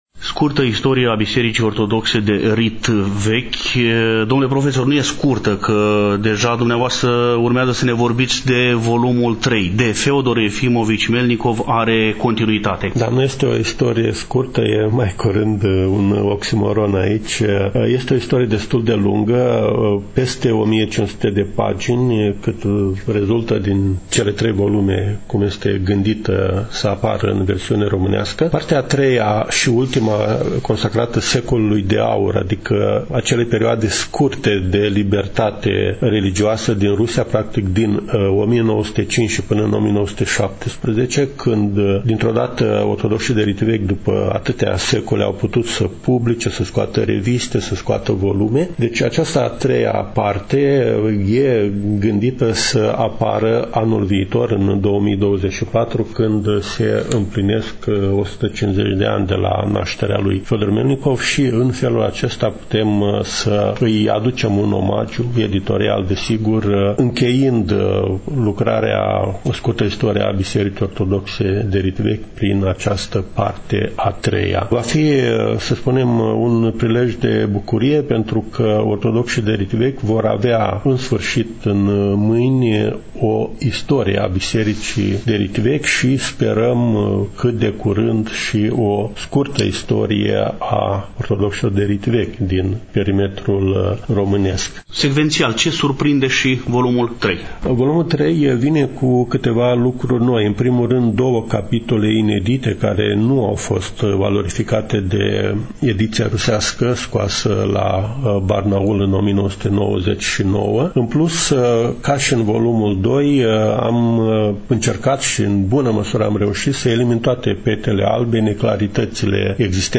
Stăm de vorbă cu domnul profesor universitar